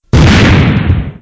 explode